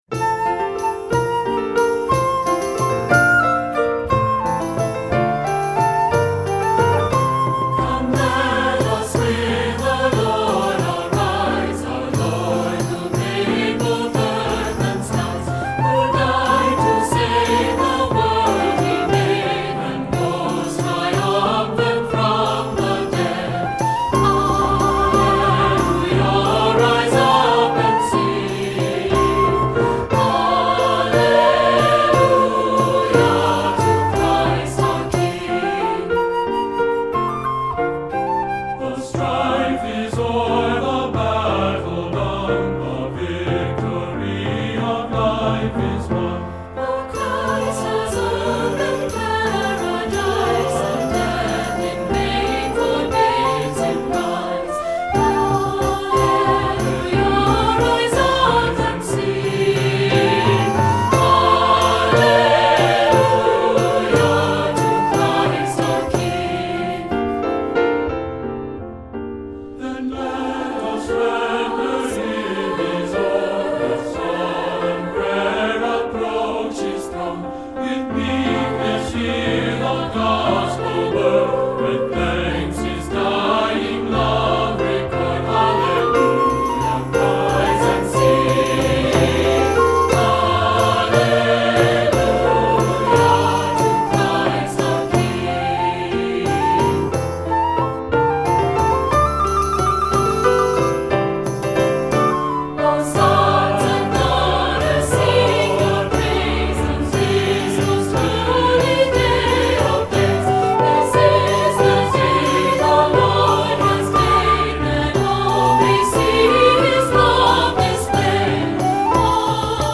Voicing: 2-part or SATB